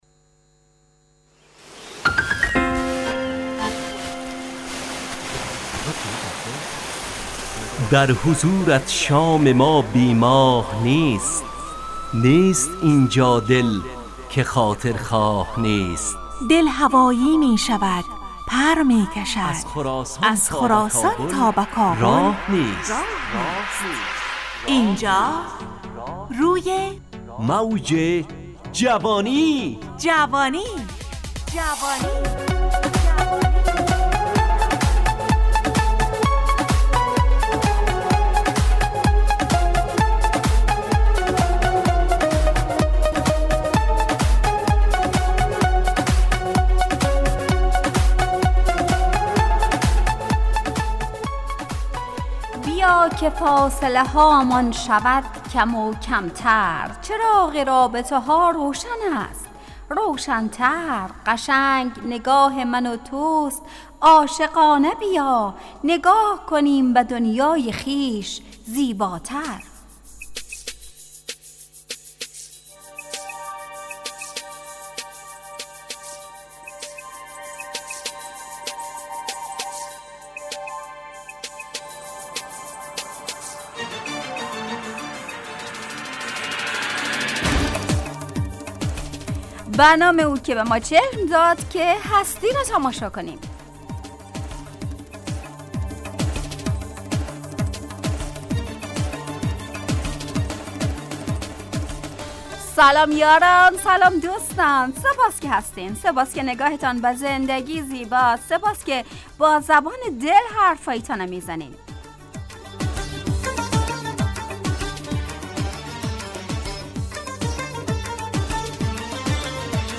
همراه با ترانه و موسیقی .